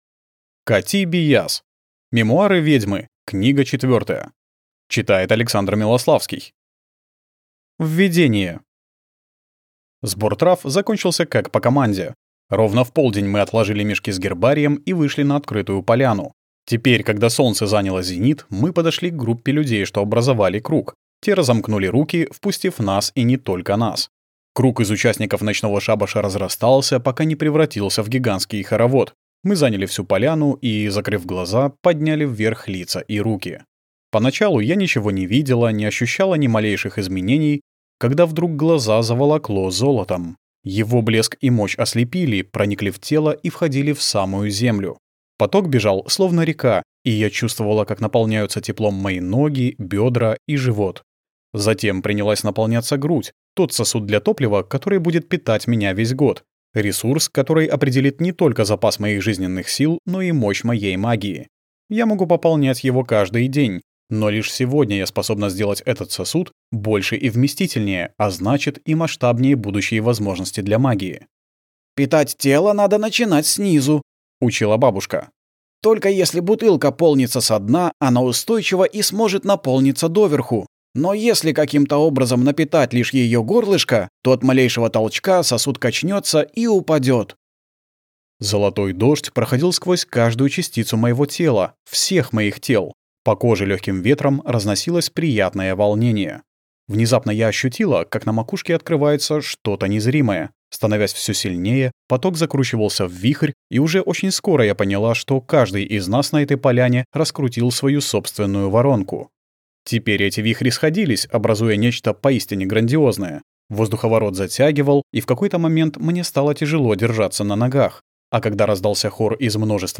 Аудиокнига Мемуары ведьмы 4 | Библиотека аудиокниг